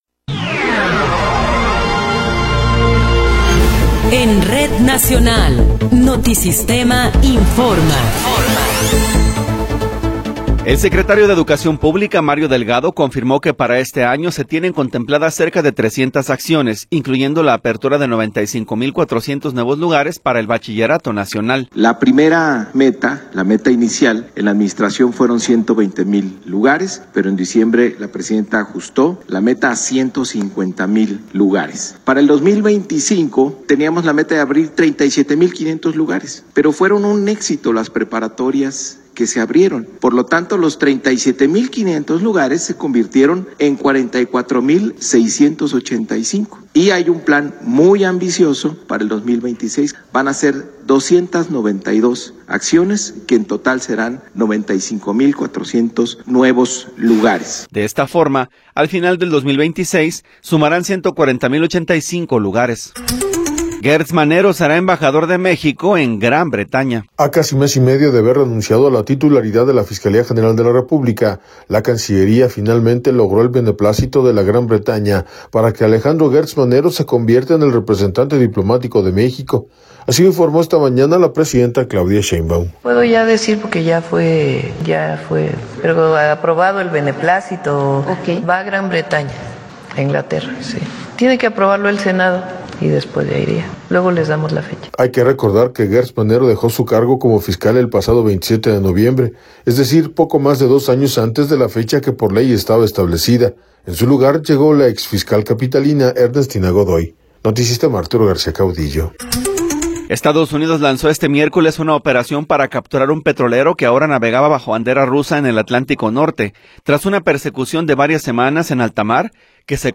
Noticiero 10 hrs. – 7 de Enero de 2026